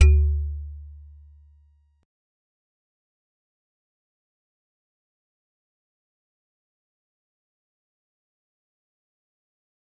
G_Musicbox-D2-pp.wav